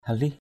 /ha-lih/ (đg.) xít, xê = se déplacer. halih tamâ hl{H tm% xít vào = approcher, rentrer. halih tabiak hl{H tb`K xê ra = s’éloigner. halih pajaik hl{H F=jK xít cho gần...